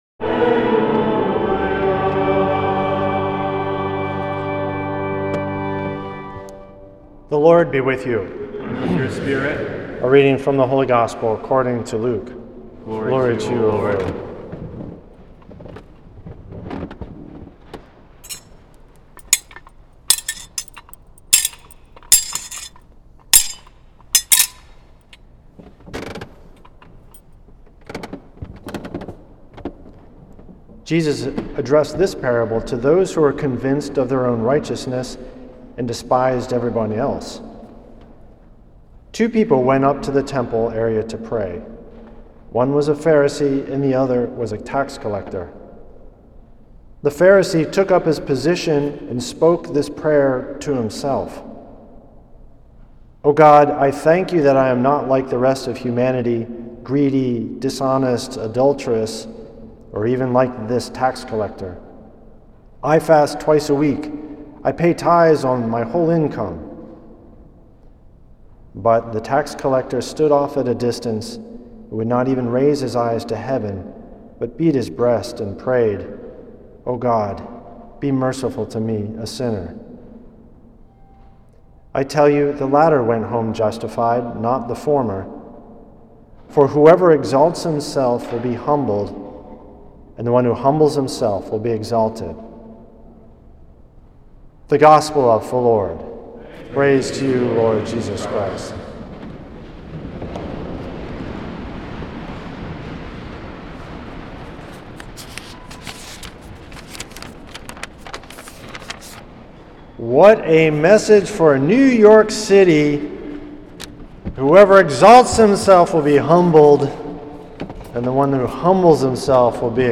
Homily
at St. Patrick’s Old Cathedral in NYC on October 28th, 2025.